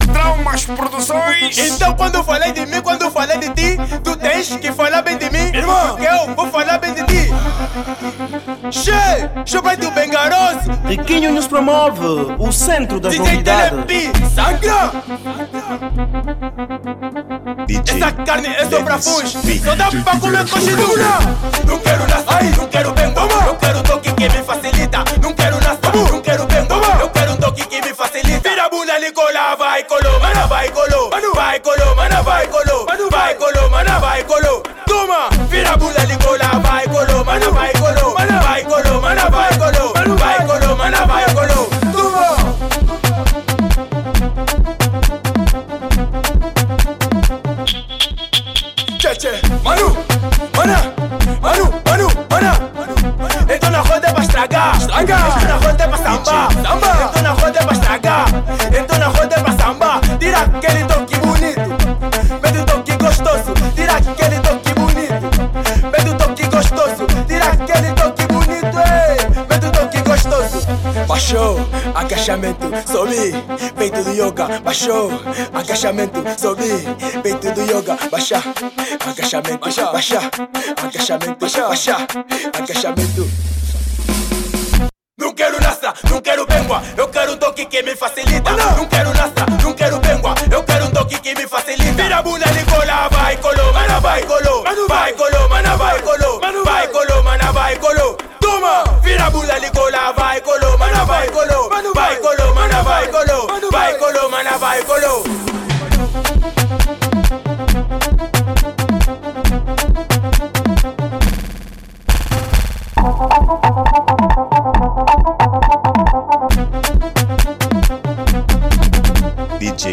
Género: Afro House